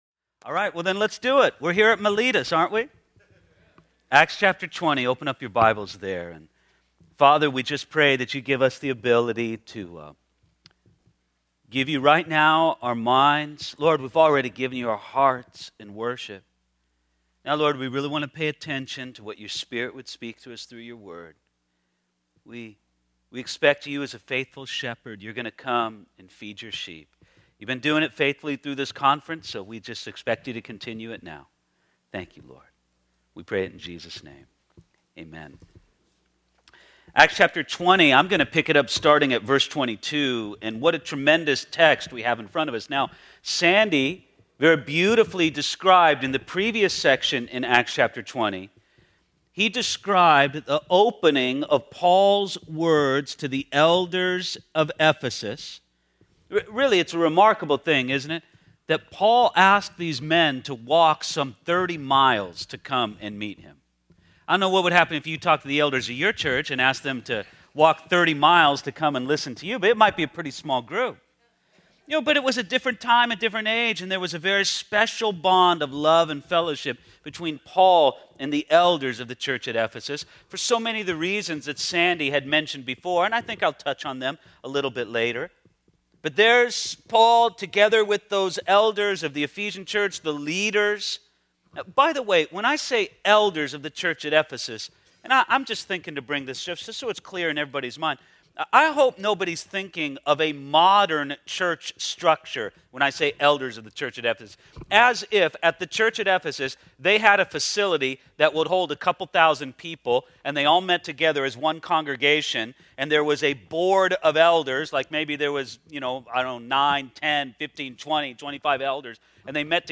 2012 DSPC Conference: Pastors & Leaders Date